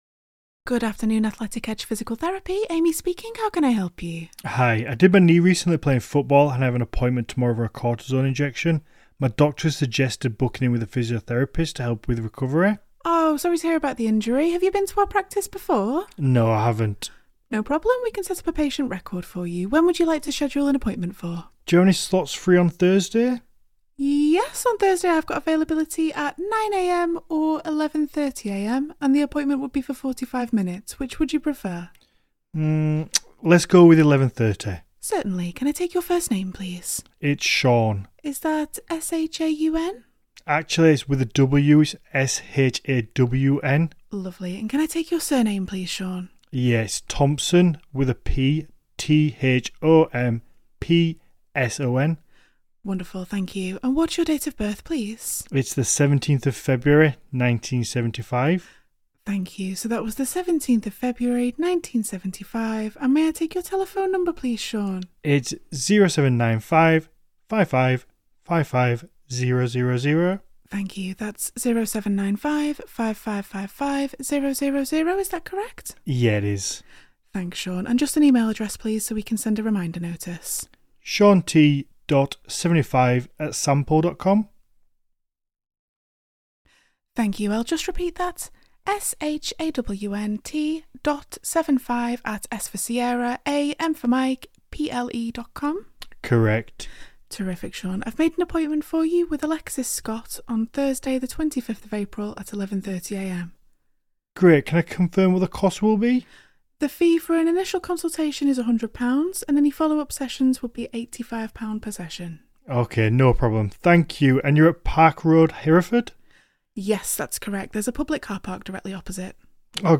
Our friendly and professional receptionists will answer your business calls with your preferred greeting, 24/7 if you wish.
medical-healthcare-virtual-receptionist-sample-call-appointment.mp3